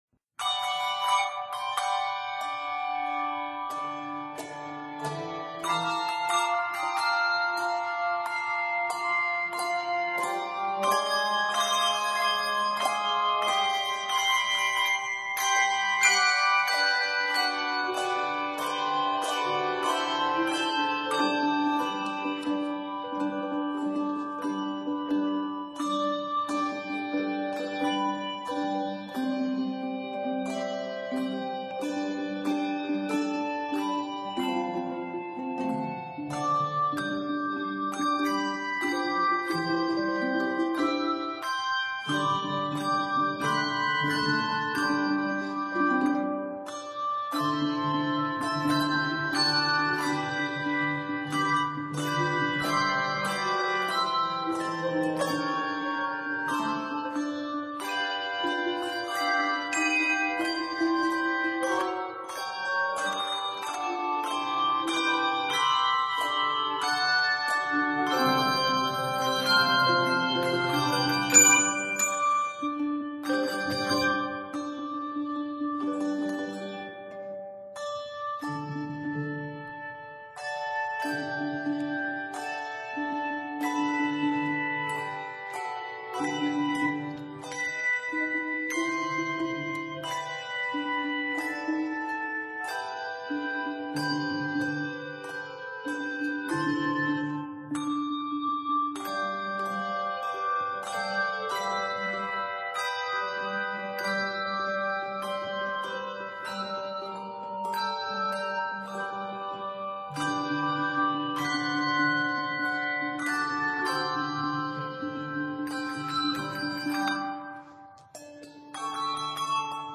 Percussive, chromatic, high-energy, and fun